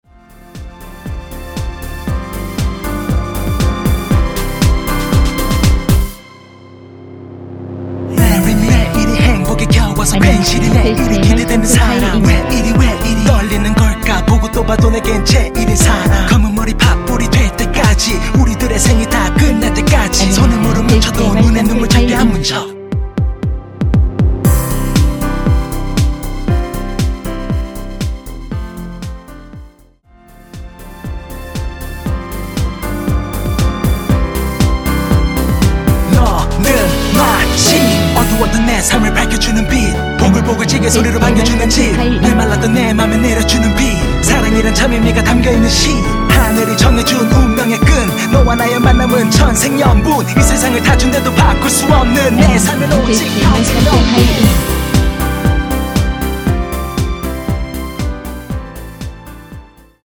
전주가 없는 곡이라 2마디 전주 만들어 놓았습니다.(원키 멜로디MR 미리듣기 참조)
원키에서(-2)내린 랩 포함된 MR입니다.(미리듣긱 확인)
앞부분30초, 뒷부분30초씩 편집해서 올려 드리고 있습니다.
중간에 음이 끈어지고 다시 나오는 이유는